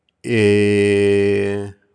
SOUNDS OF THE ROMANIAN LANGUAGE
Vowels